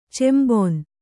♪ cembon